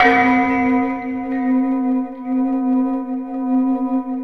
POWERBELL C4.wav